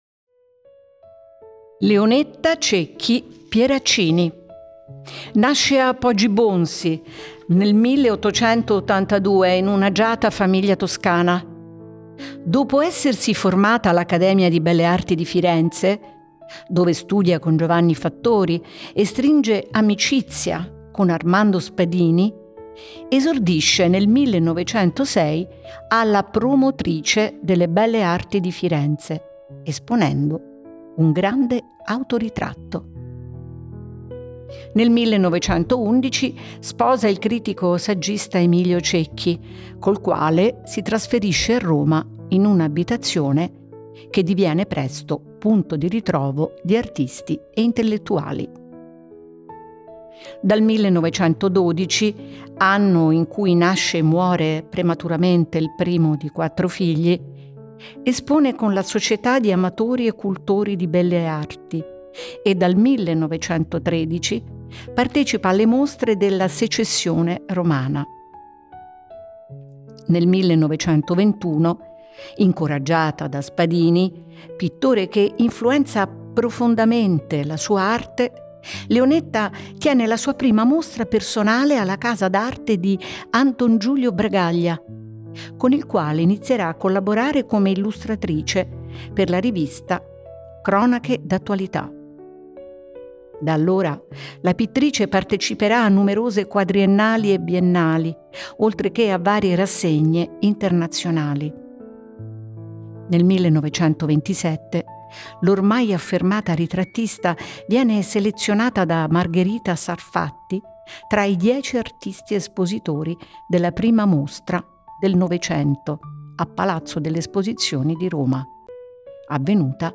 AUDIOGUIDA
Registrazioni effettuate presso Technotown